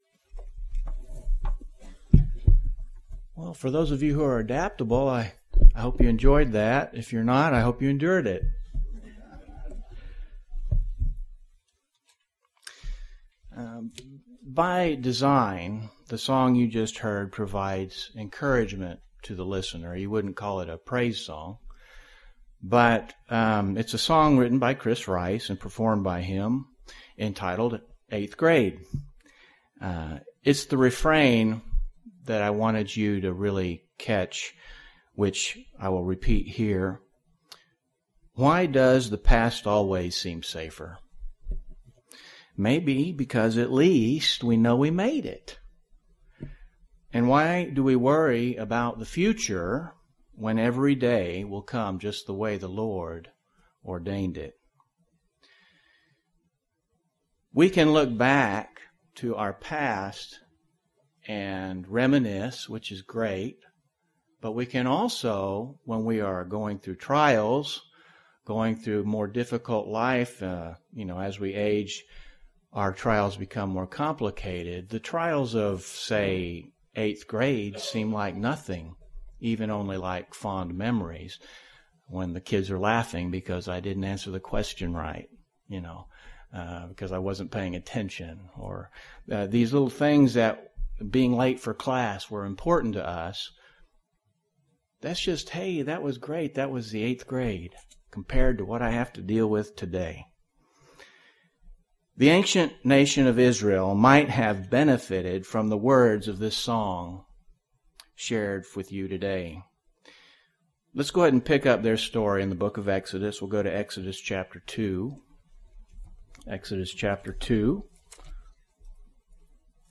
By design, the song you just heard exists to provide encouragement to the listener.